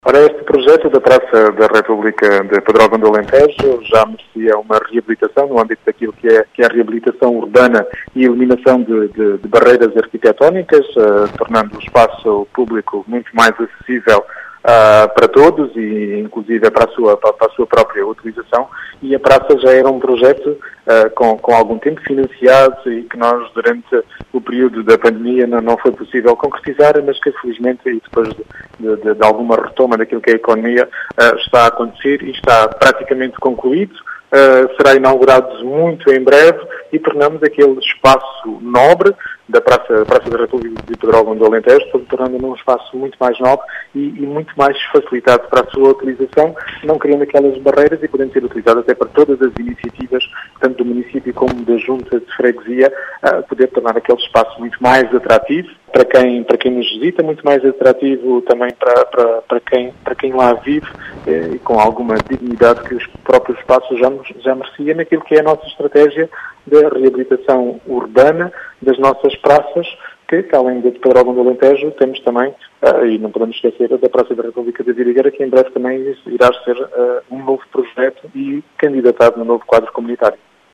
As explicações são de Rui Raposo, presidente da Câmara de Vidigueira, que afirma que aquele será um “espaço nobre” e será inaugurado brevemente.